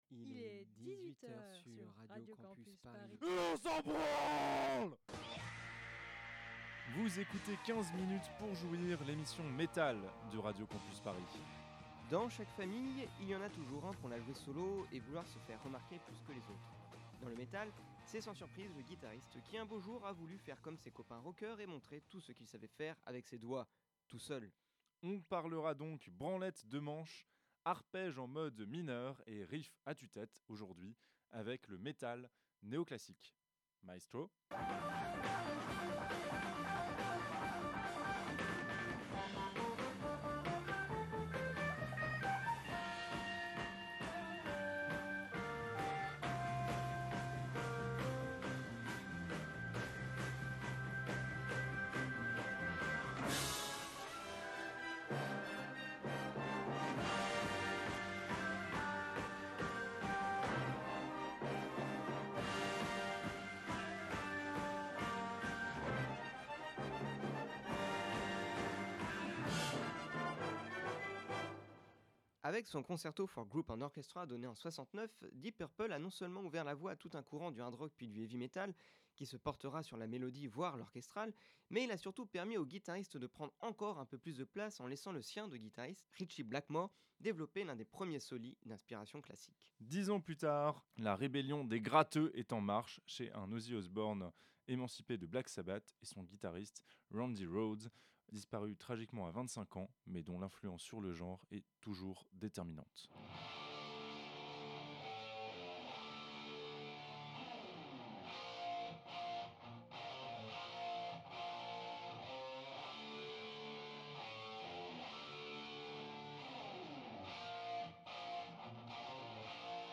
Car le Néo-classique, vois-tu, c'est un peu la rencontre entre un vieil amateur de musique baroque et un jeune métalleux arrogant. Ça passe ou ça casse, en tous cas ça marche, en arpège et doubles-croches.
C'est un virtuose de la gratte et c'est au fond ce qu'on lui demande : nous émoustiller en quatre saisons ou au clair de la lune.